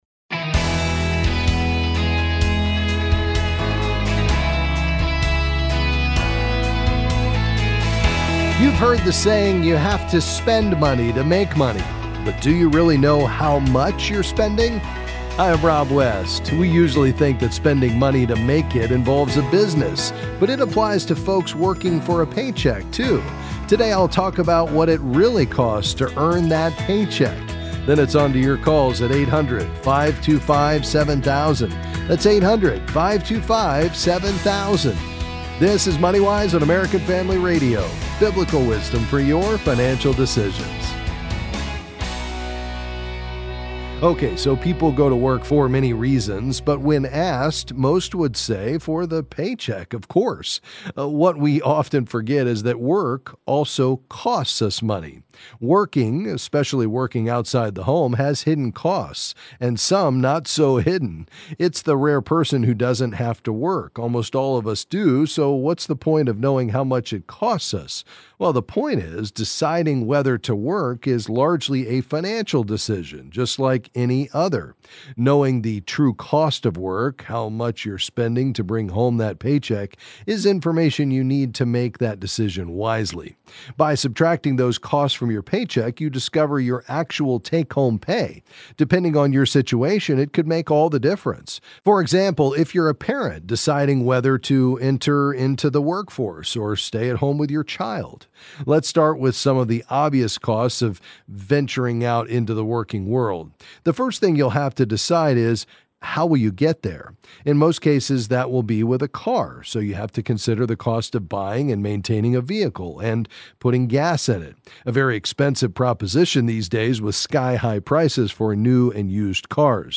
Then he’ll answer your calls and questions on any financial topic.